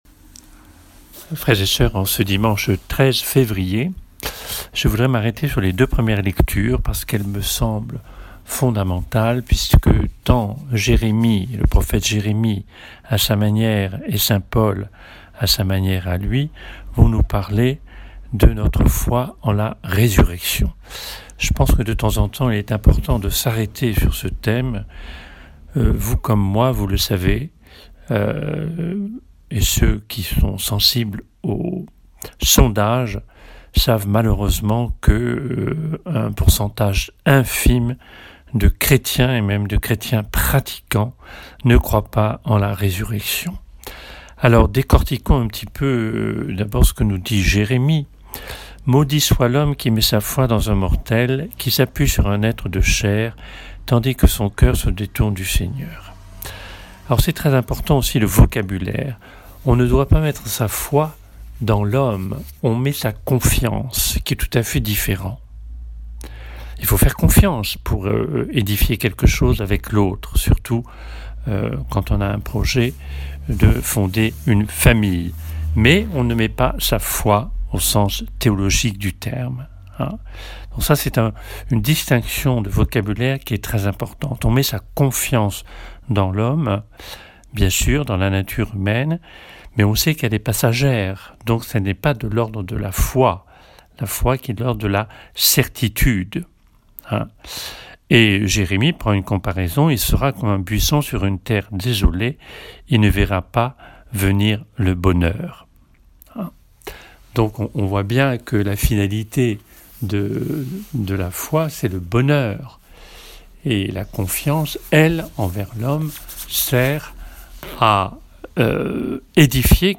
Homélie